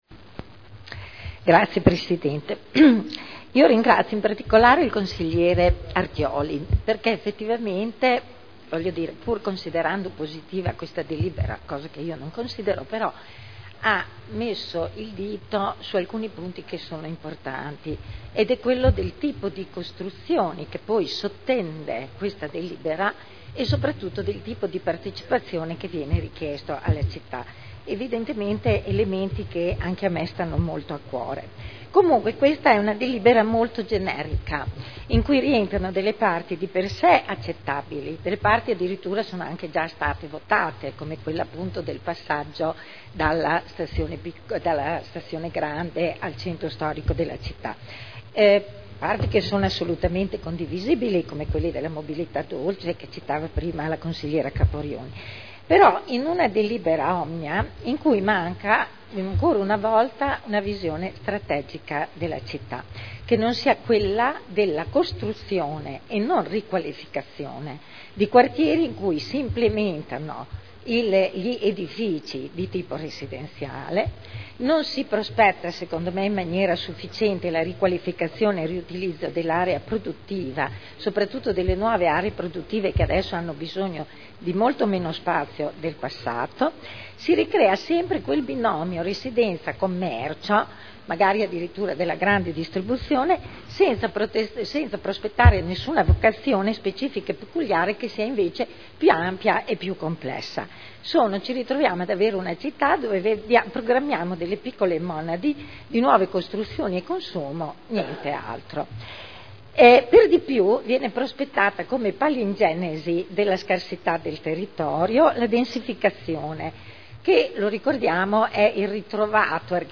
Seduta del 01/02/2010. La Città della riqualificazione – Programma di riqualificazione urbana per il quadrante urbano di Modena Ovest – Approvazione del documento di indirizzo